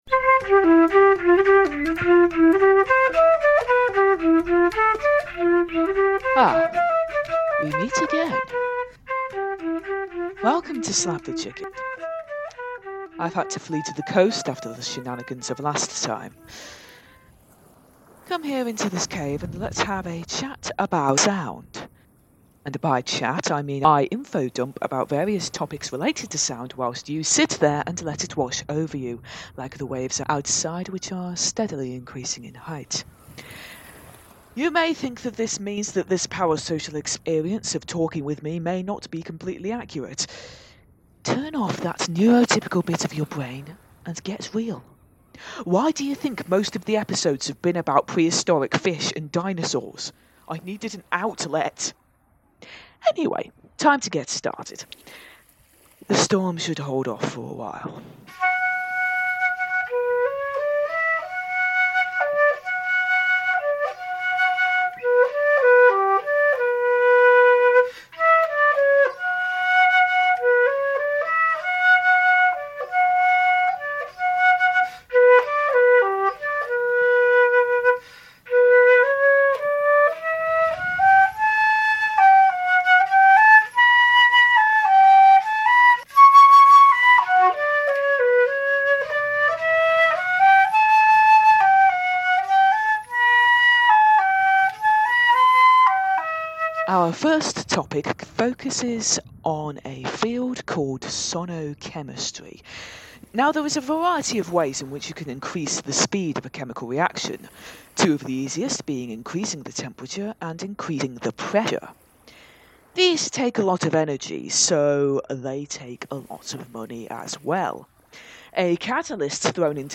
The crackling at the start was left in due to irony and incompetence.